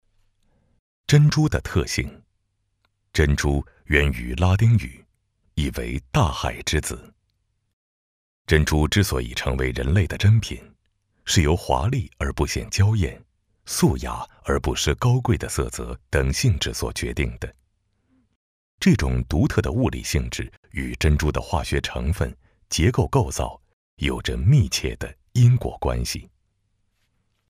成熟稳重 产品解说
声音特点：大气沉稳，浑厚低沉，深沉底蕴，擅长不同题材配音，作品：安徽旅游宣传片，央视公益配音等